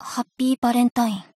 贡献 ） 协议：Copyright，其他分类： 分类:爱慕织姬语音 您不可以覆盖此文件。